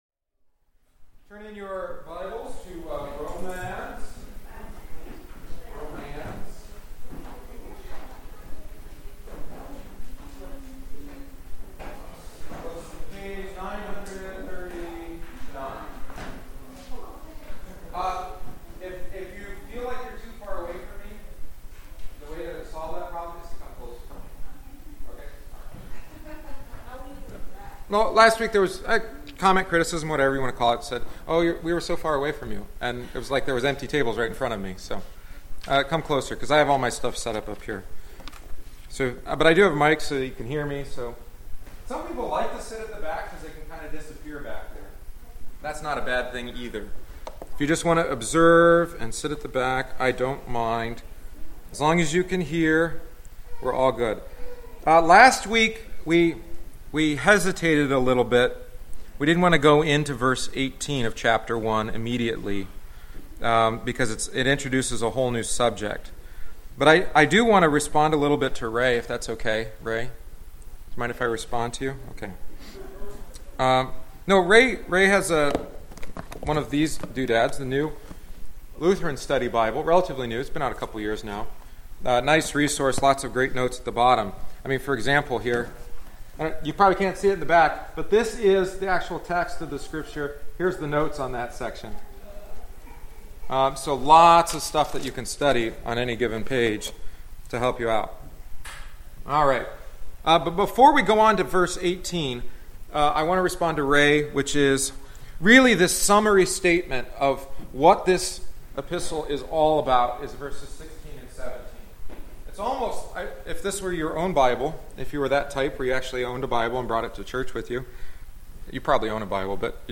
If you were unable to join us for our continued study of St. Paul’s letter to the church in Rome, attached is the third week’s lesson. We began with an excursus on who both God’s salvation and wrath are revealed and belong to God’s righteousness.